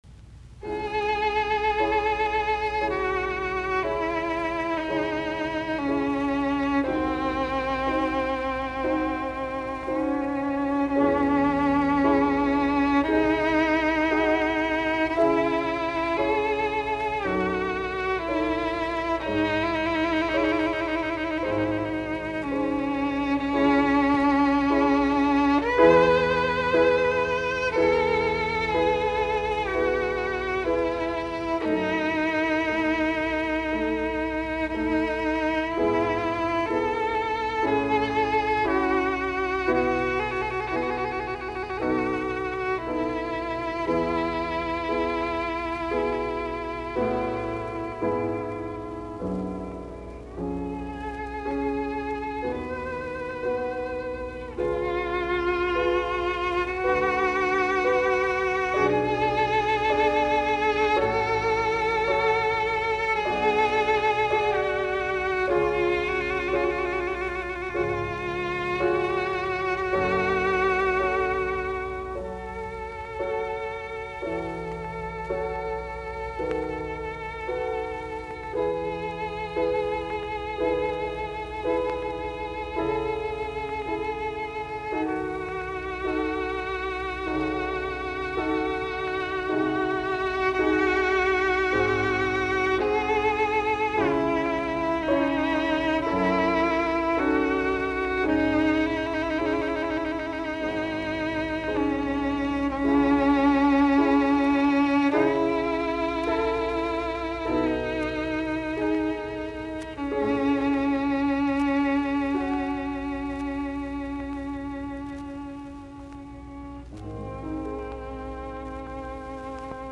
sov. viulu, piano
Soitinnus: Viulu, piano.